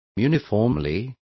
Also find out how uniformemente is pronounced correctly.